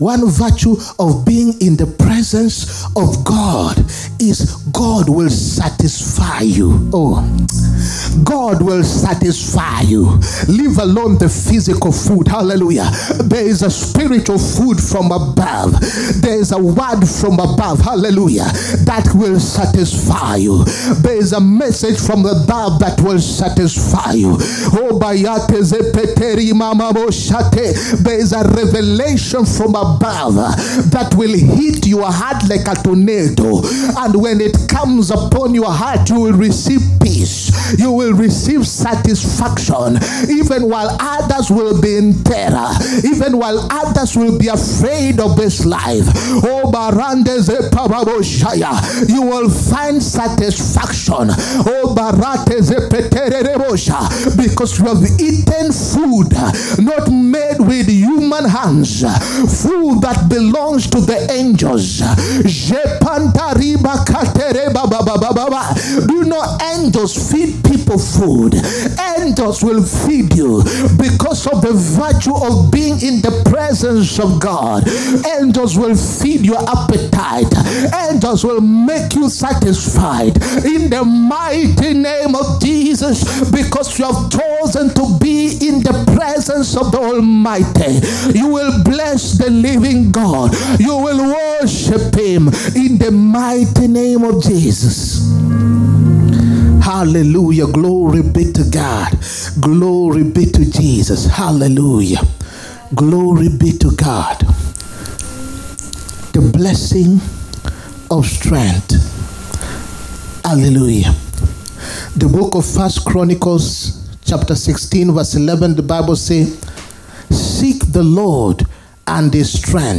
HEALING, PROPHETIC AND DELIVERANCE SERVICE. 27TH JULY 2024. PART 2.